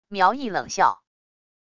苗毅冷笑wav音频